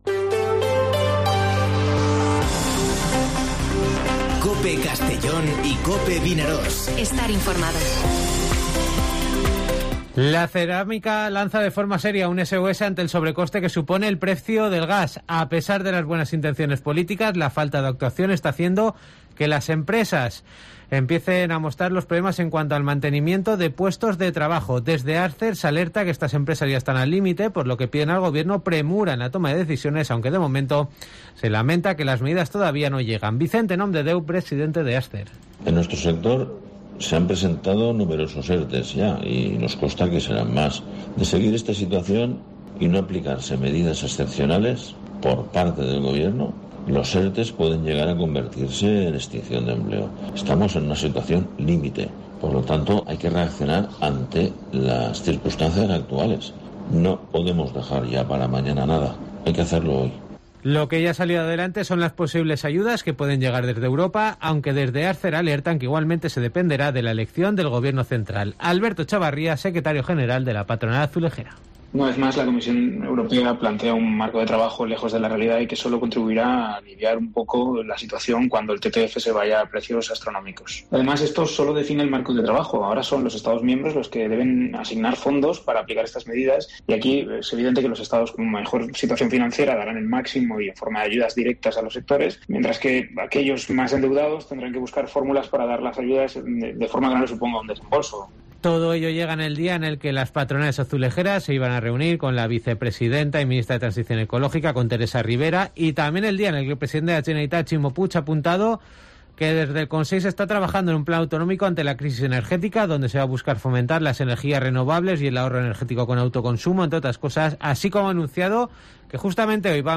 Informativo Mediodía COPE en Castellón (24/03/2022)